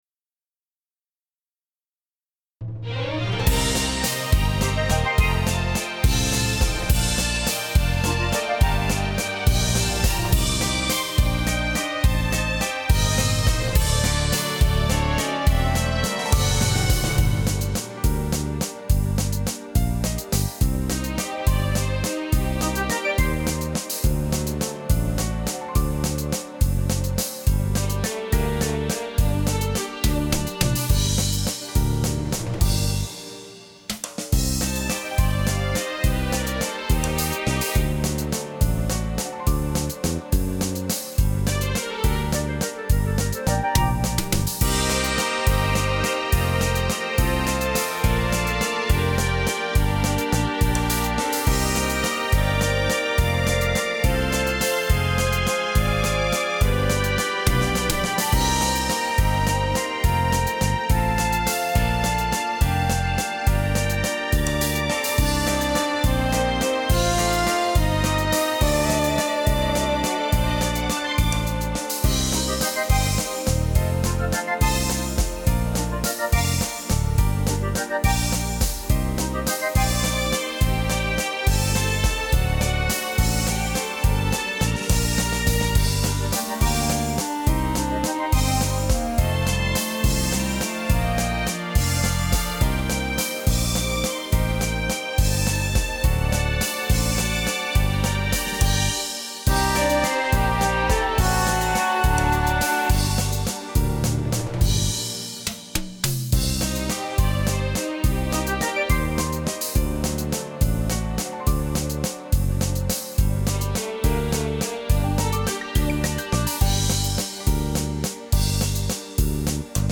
караоке
Скачать минус детской песни